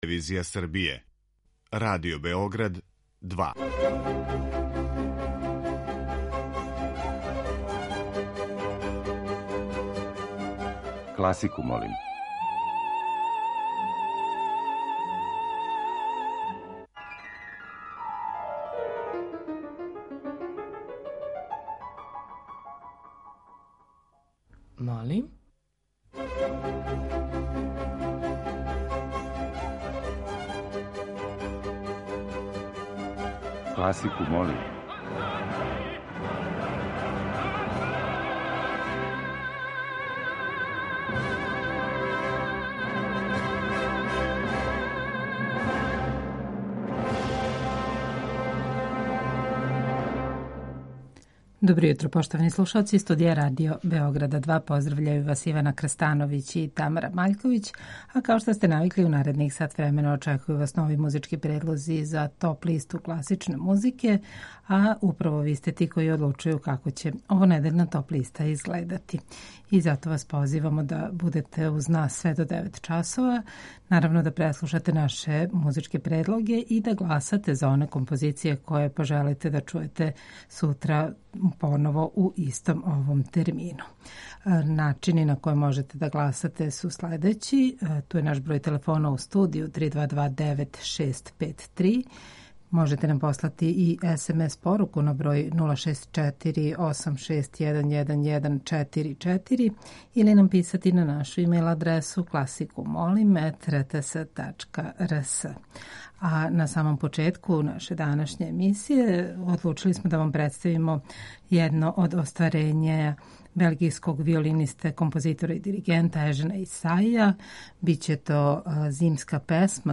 Уживо вођена емисија Класику, молим окренута je широком кругу љубитеља музике и разноврсног је садржаја ‒ подједнако су заступљени сви музички стилови, епохе и жанрови.